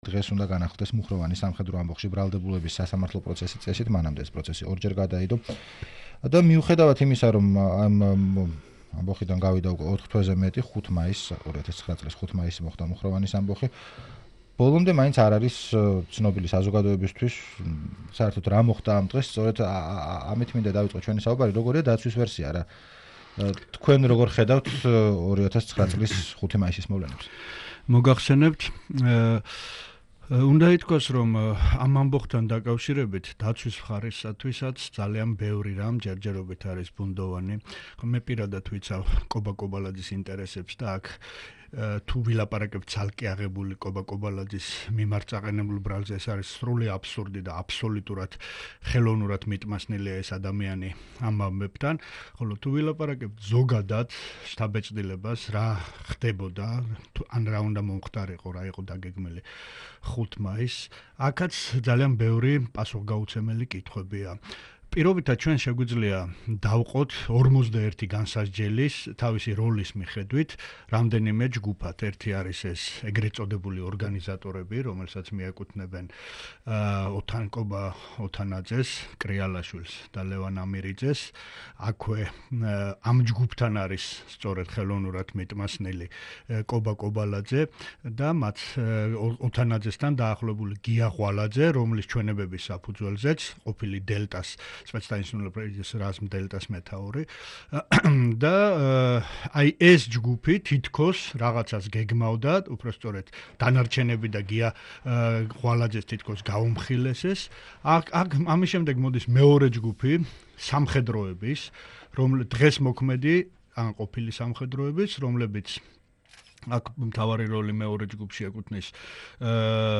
რადიო თავისუფლების თბილისის სტუდიას სტუმრობდა